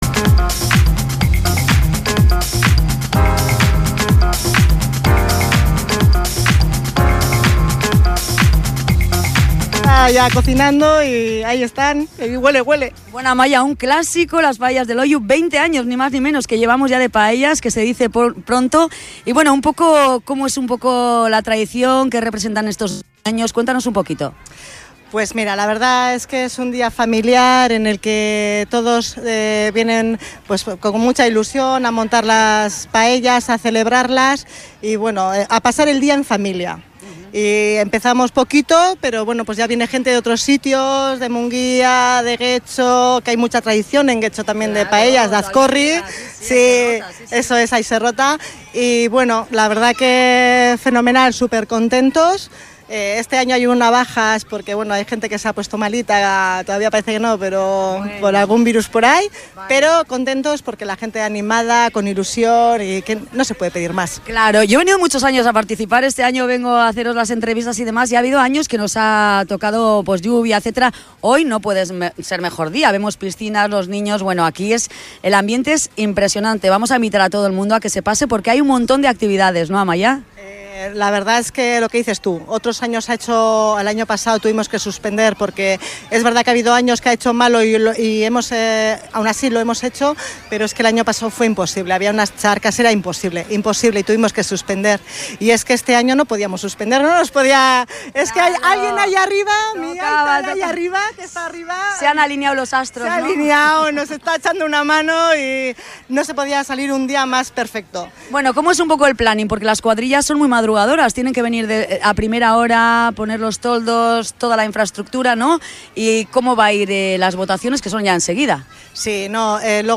Entrevistas
Escucha el programa especial de Radio Nervión con entrevistas durante todo el concurso